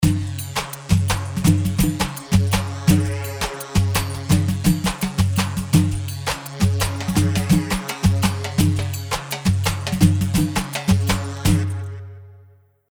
Basta 4/4 168 بستة
Basta-4-4-168.mp3